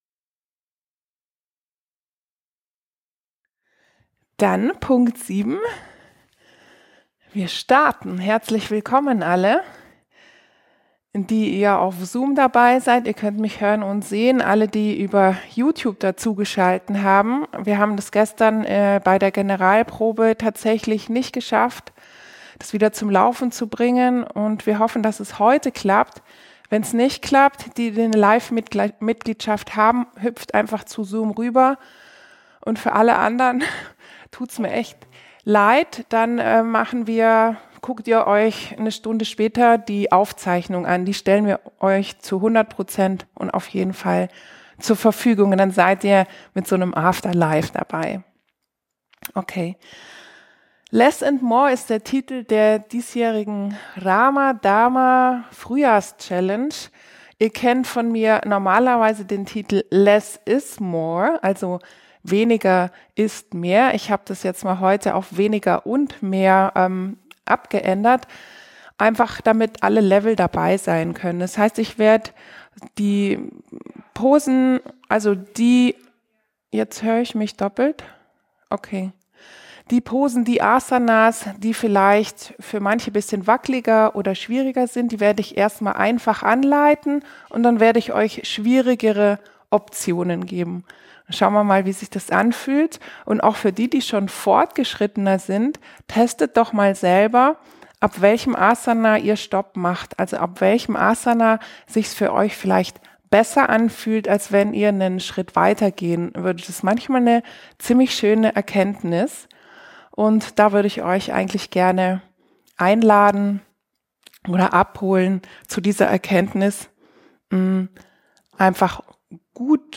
Welcome-Yogasession (Vinyasa Yoga)